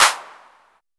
808clap.wav